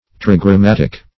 Search Result for " trigrammatic" : The Collaborative International Dictionary of English v.0.48: Trigrammatic \Tri`gram*mat"ic\, a. [Gr.
trigrammatic.mp3